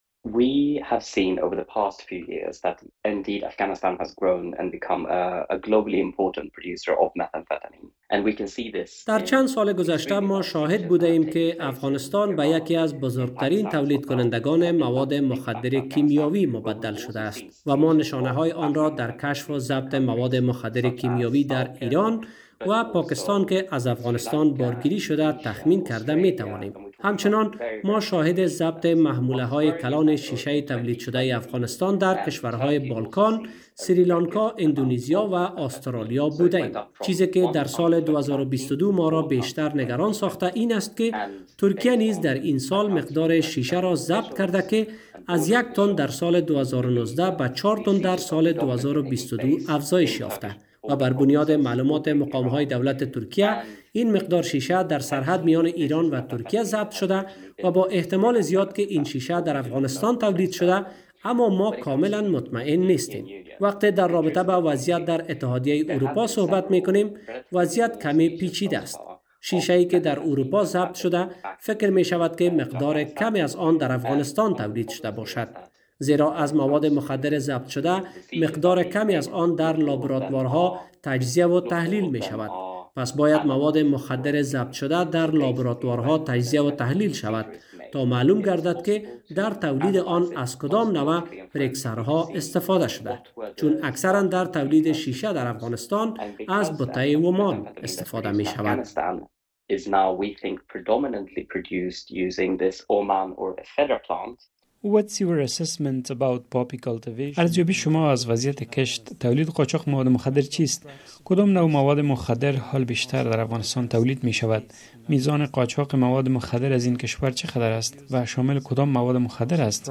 کارمند ارشد مرکز نظارتی اروپا بر مواد مخدر و اعتیاد مصاحبه کردیم. در این مصاحبه از وی در رابطه به نقش افغانستان در عرضه مواد مخدر به بازارهای اروپایی و...